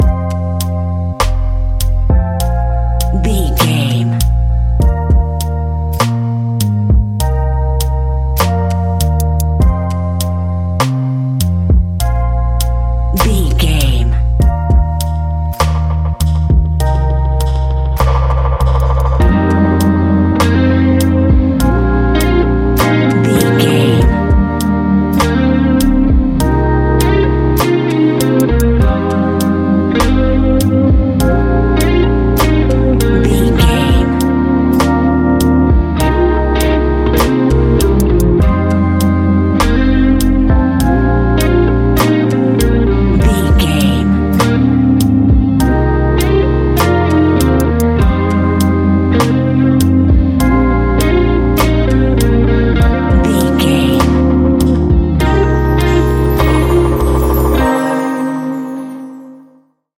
Ionian/Major
A♯
chilled
laid back
Lounge
sparse
new age
chilled electronica
ambient
atmospheric
morphing